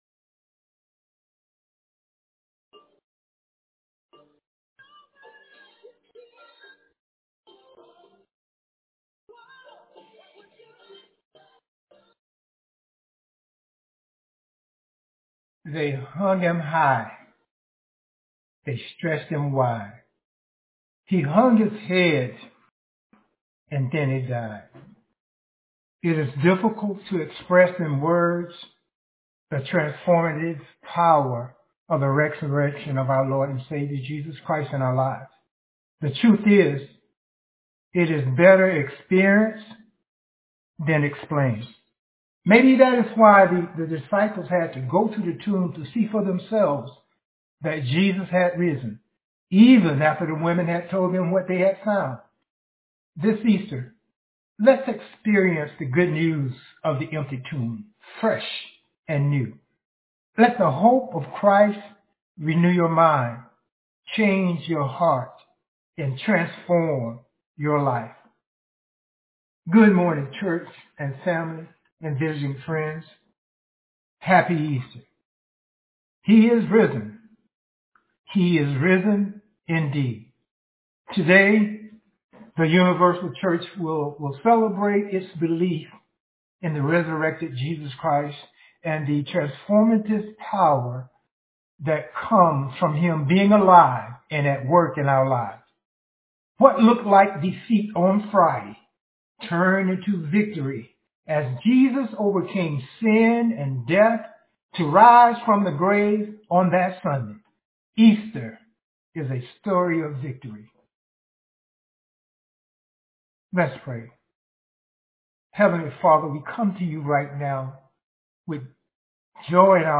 0 Share this sermon